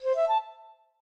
flute_ceg.ogg